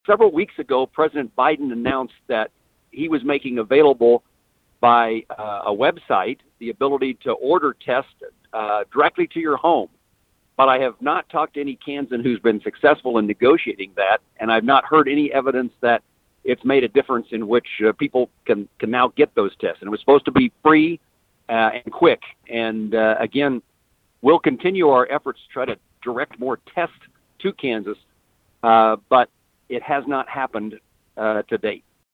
Prior to that meeting, Moran had a one-on-one interview with KVOE News, which largely dealt with the current COVID-19 situation. Moran says supply issues, notably with testing but with other items as well, could have been resolved with a stronger, earlier response from the White House.